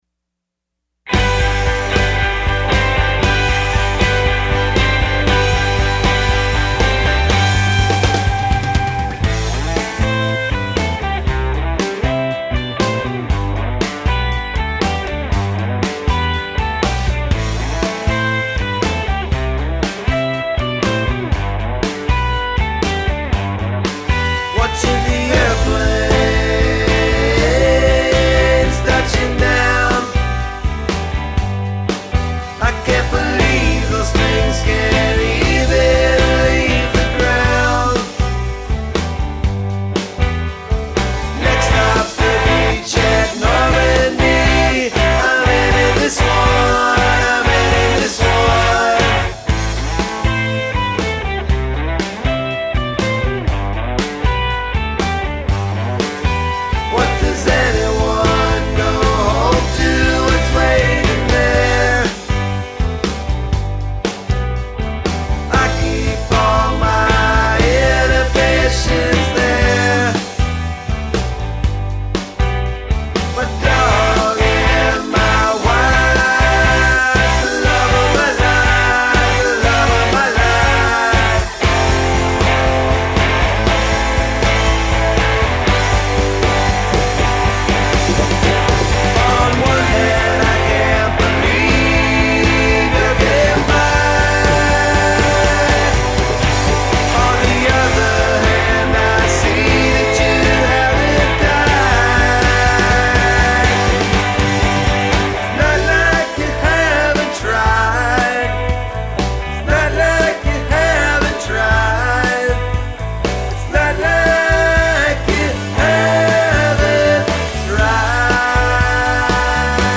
Lead Vocals and Guitar
Bass Guitar and Vocals
Drums
Vocals and Guitar